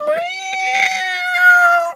pgs/Assets/Audio/Animal_Impersonations/cat_2_meow_long_06.wav at master
cat_2_meow_long_06.wav